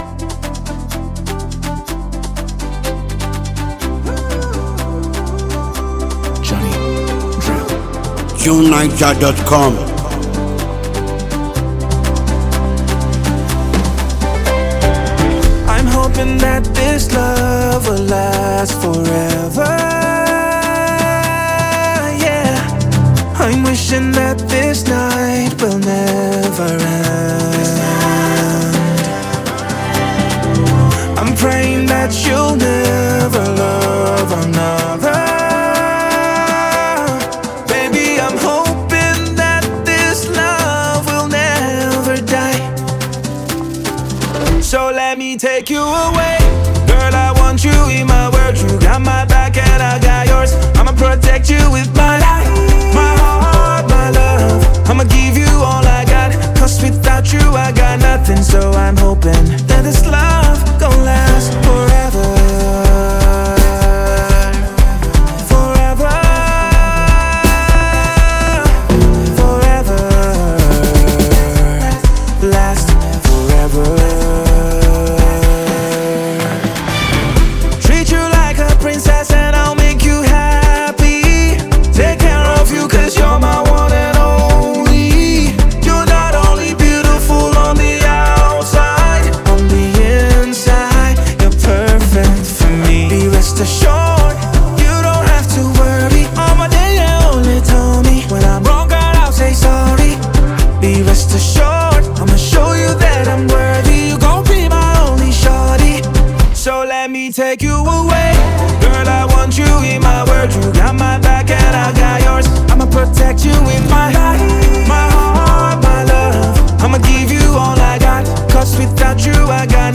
Nigerian singer-songwriter